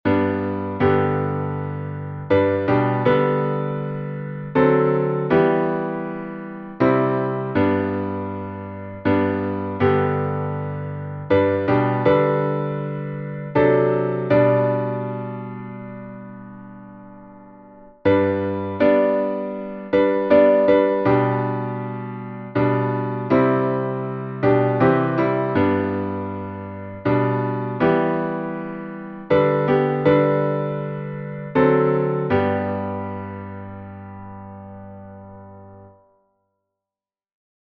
salmo_3B_instrumental.mp3